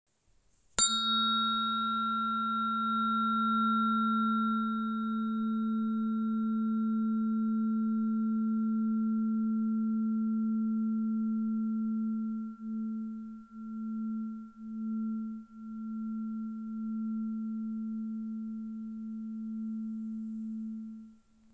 🌬 Hliníková terapeutická ladička 220 Hz – Plíce
Jemný tón této frekvence uklidňuje, rozšiřuje hrudník a pomáhá uvolnit napětí, které se často hromadí při stresu, smutku nebo potlačeném dechu.
• 220 Hz odpovídá tónu A3, který leží v přirozeném rozsahu lidského hlasu – zejména ženského.
• Frekvence: 220 Hz (A3)
Terapeutické ladičky z naší české dílny jsou vyrobené z prvotřídního hliníku, který poskytuje výjimečně čistou a dlouhotrvající vibraci.